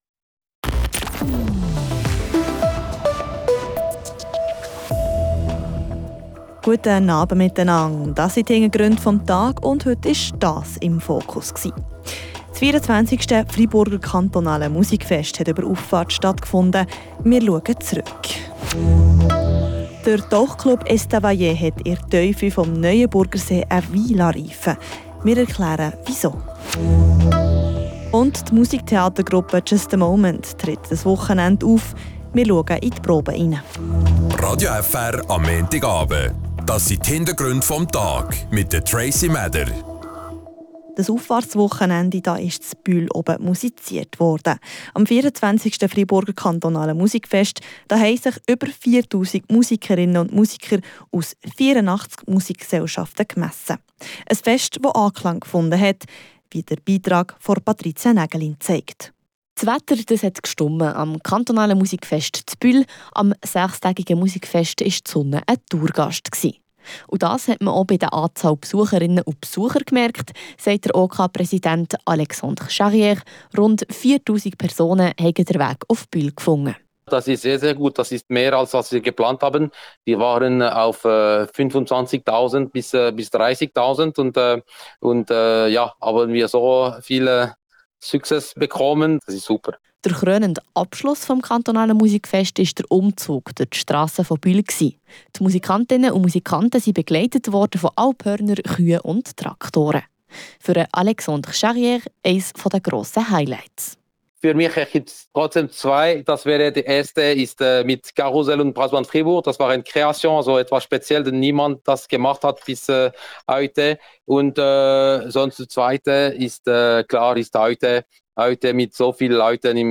Wir waren bei den Proben dabei.